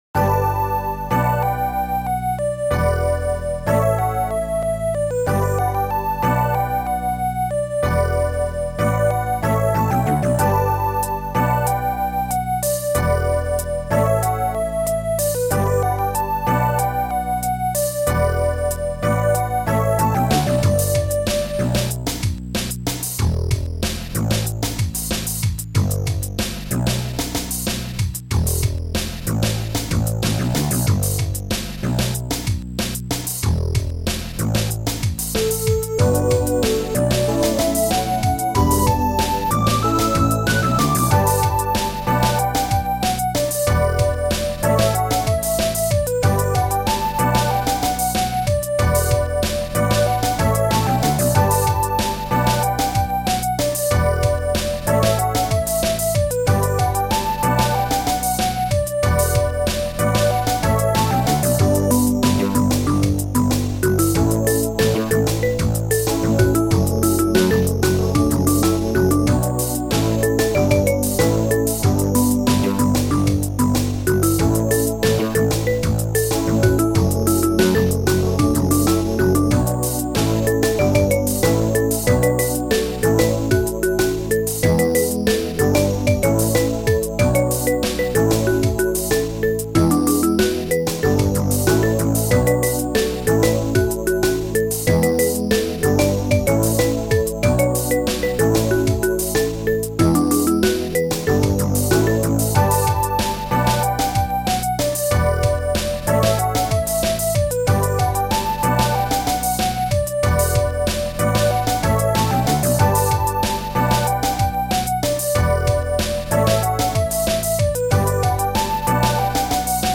Sound Style: Mellow / Synth Pop / Melody